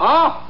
Ah Sound Effect
Download a high-quality ah sound effect.
ah-1.mp3